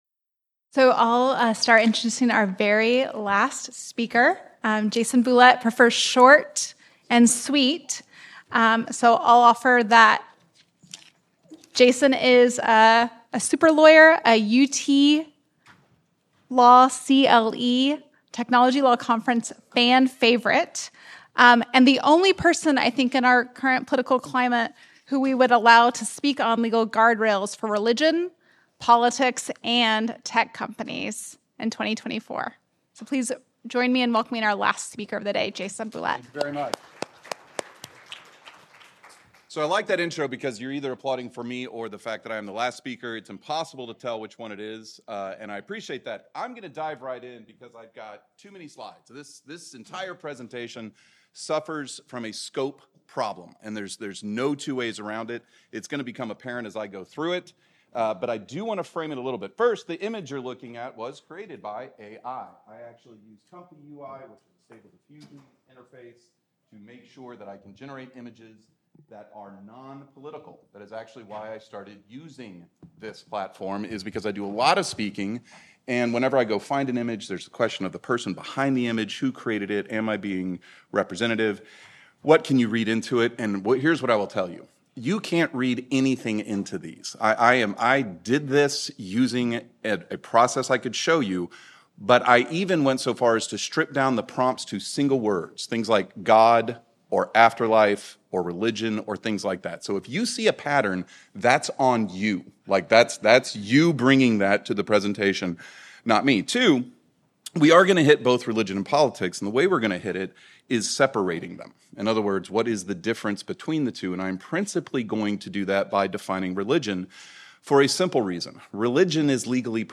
Originally presented: May 2024 Technology Law Conference